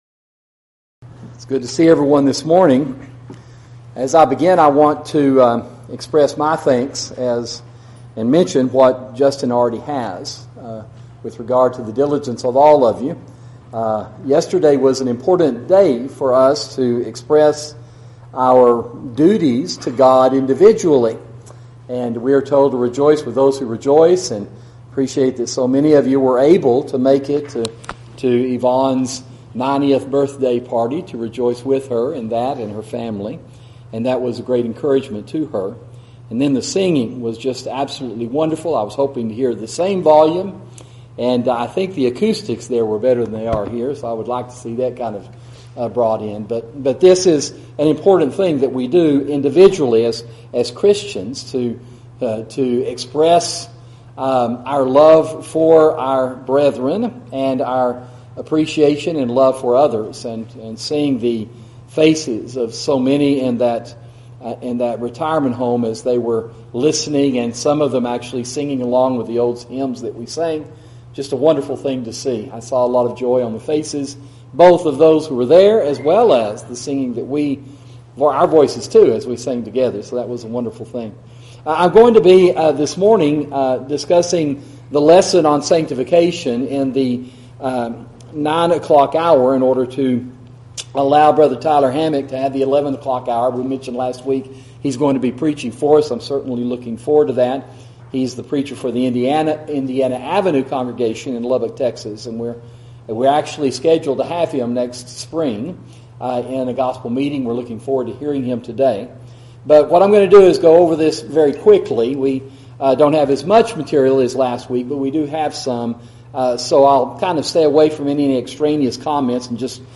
Sermon: Sanctification and Your Speech – Sound Teaching